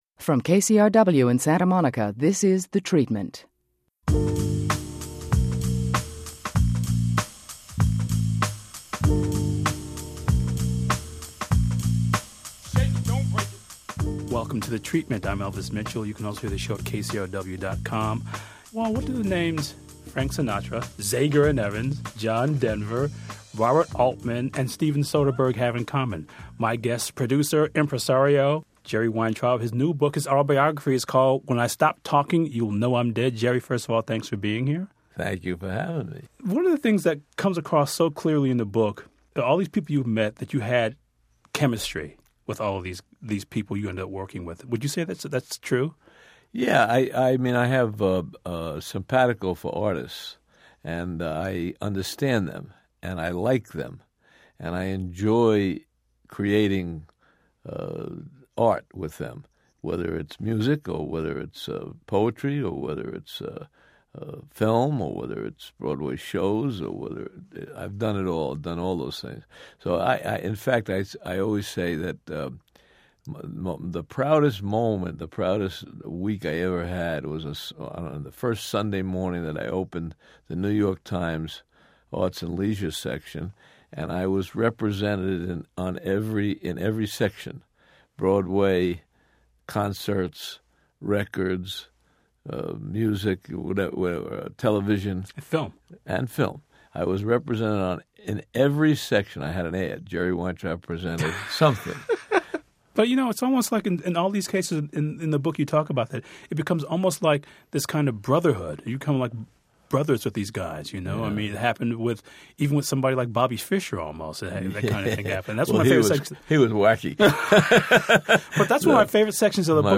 We rebroadcast our 2010 interview with producer and Hollywood impresario Jerry Weintraub, currently the subject of the HBO documentary, His Way.